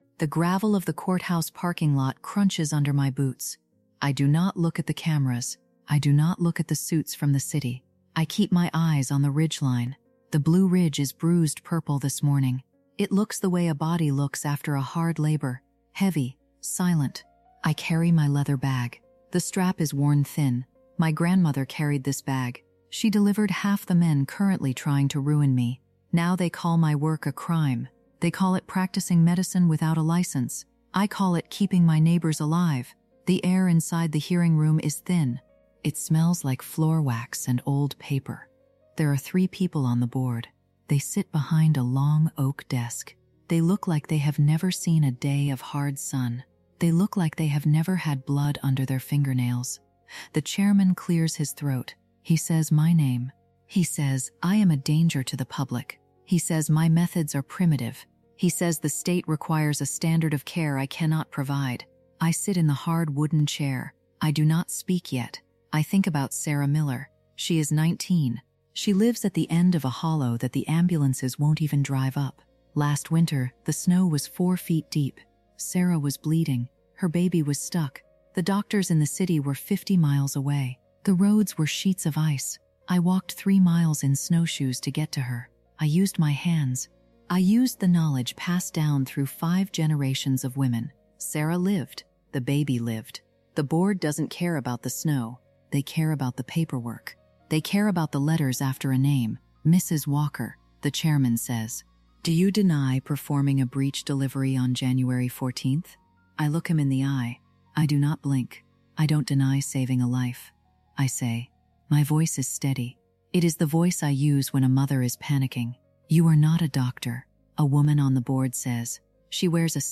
This emotionally immersive first-person story explores the heavy cost of ancestral knowledge and the grit required to stand for one's calling when the world turns against it.